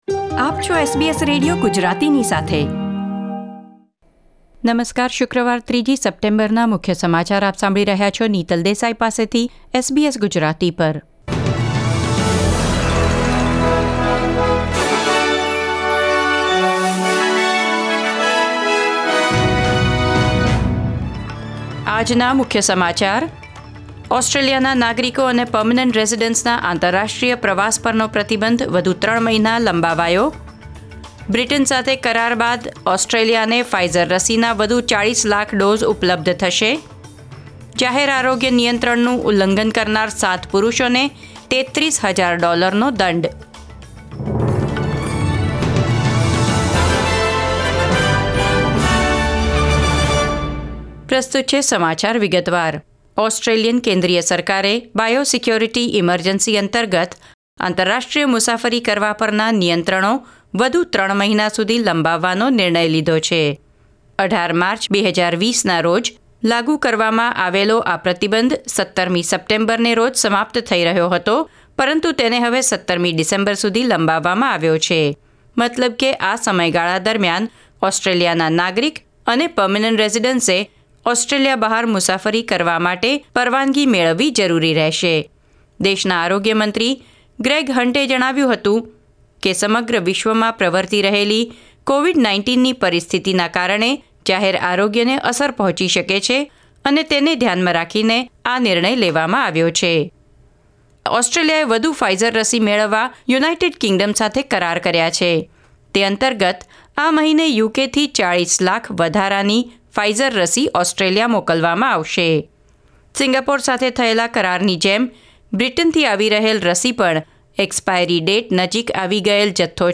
SBS Gujarati News Bulletin 3 September 2021